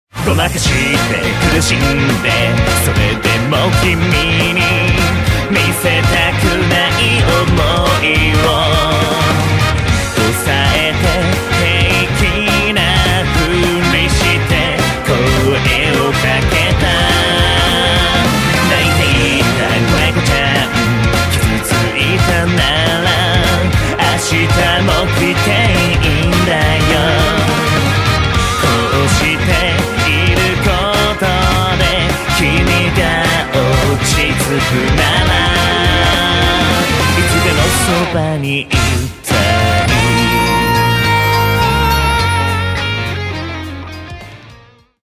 キャラクターソング